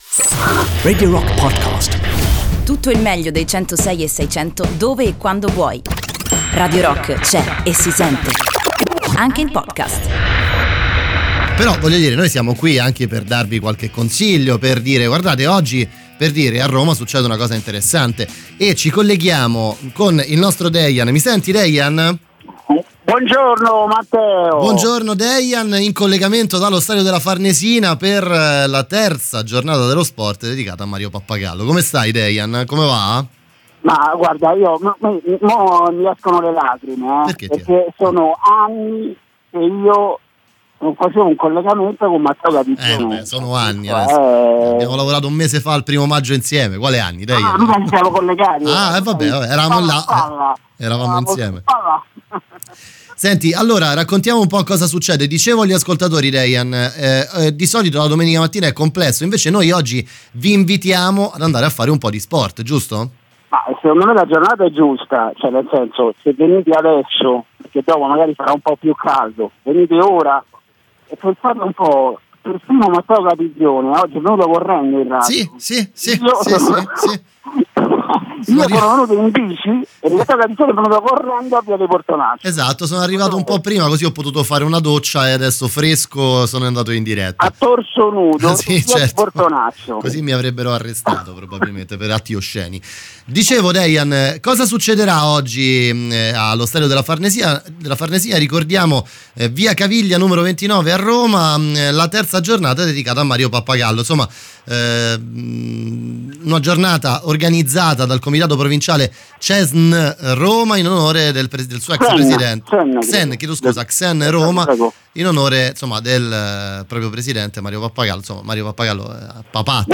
Intervista: Terza giornata dello sport "Mario Pappagallo" (09-06-19)